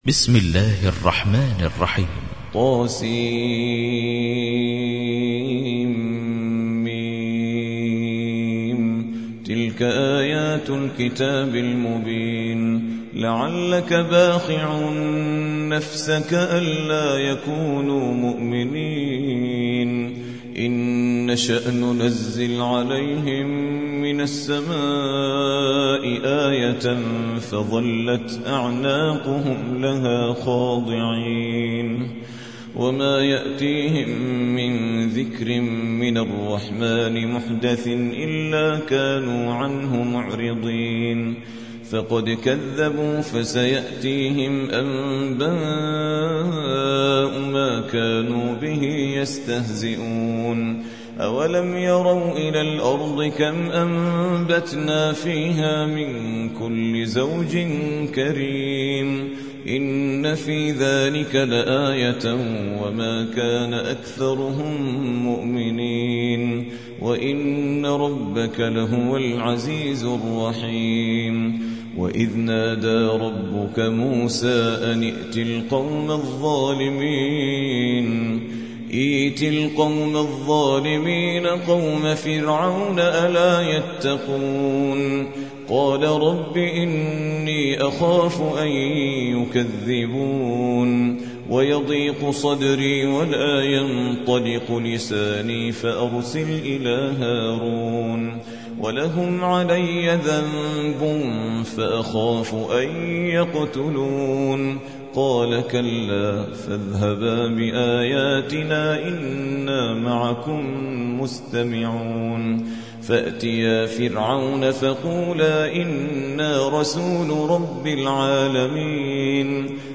26. سورة الشعراء / القارئ